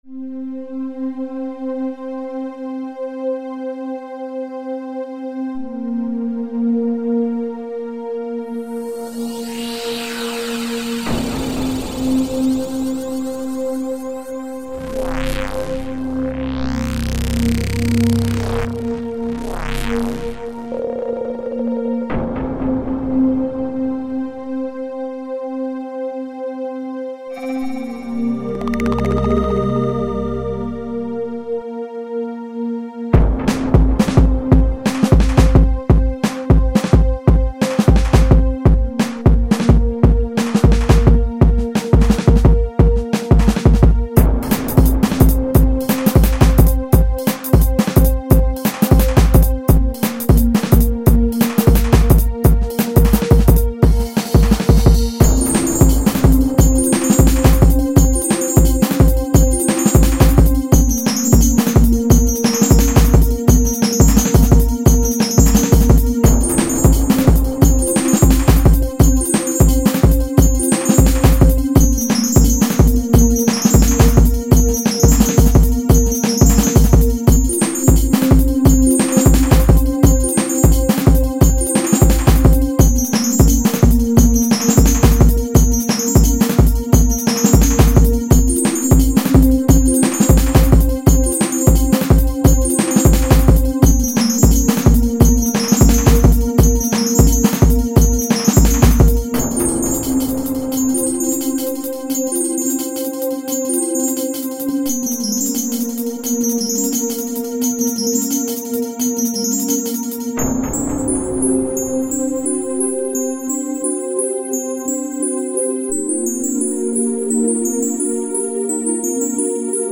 2008-2009 Электронная